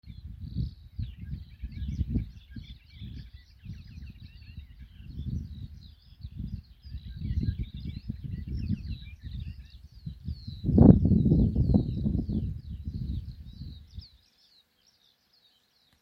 Klusais ķauķis, Iduna caligata
StatussDzied ligzdošanai piemērotā biotopā (D)